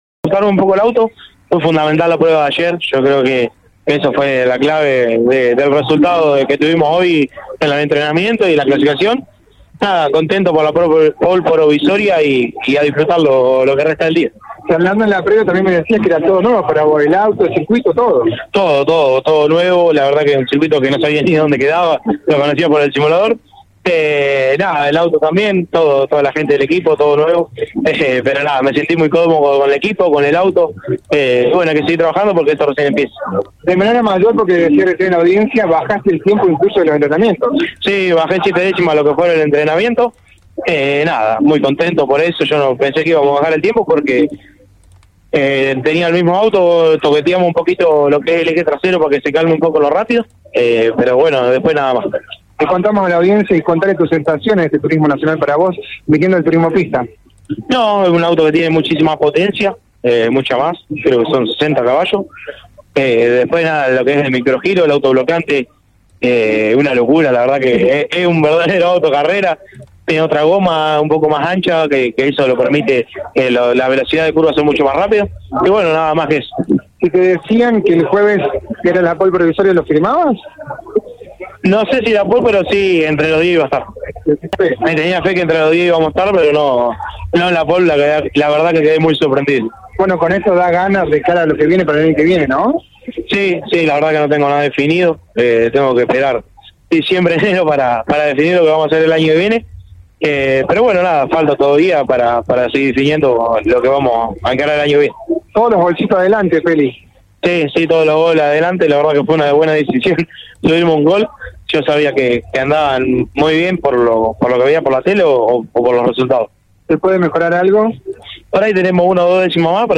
CÓRDOBA COMPETICIÓN está presente en la competencia y, por ello, dialogó en exclusiva con el propio poleman que decía lo siguiente: